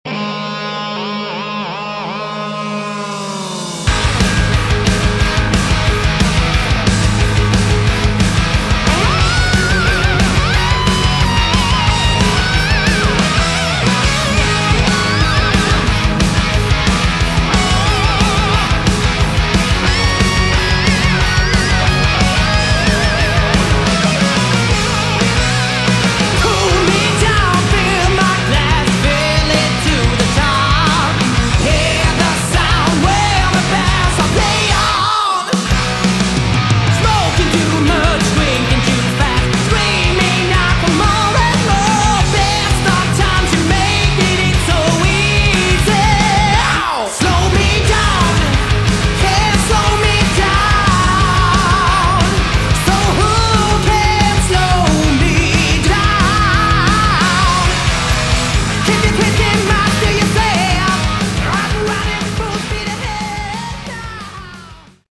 Category: Melodic Metal
This is 80s METAL.